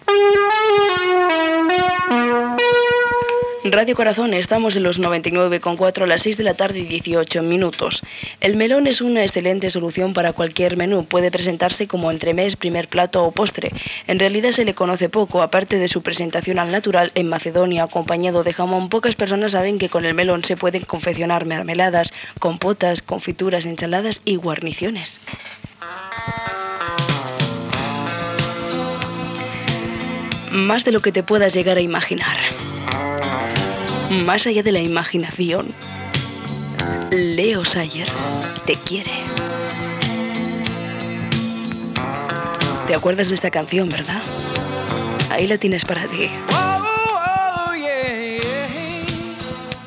Banda FM